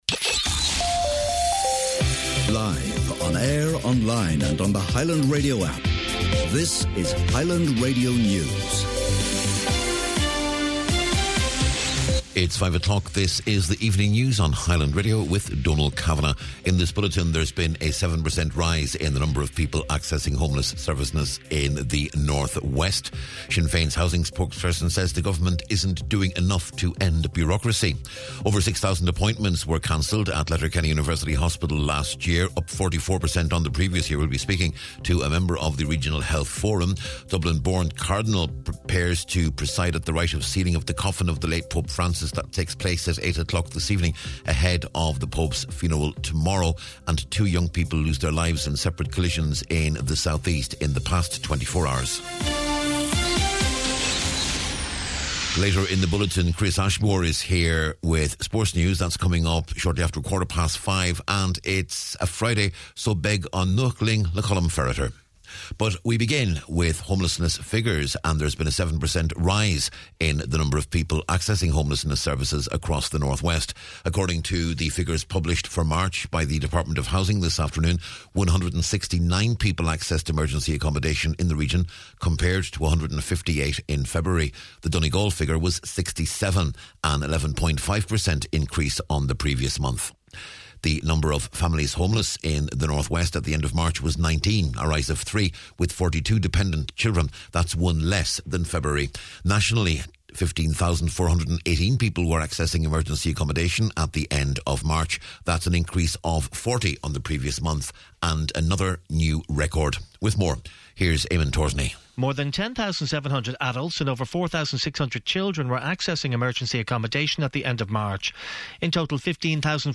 News, Sport, Nuacht and Obituaries on Friday April 25th